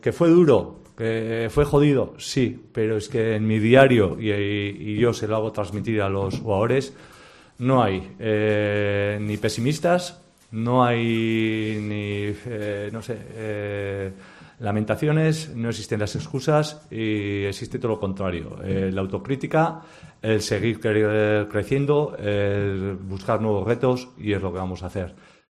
IMANOL ALGUACIL | PRENSA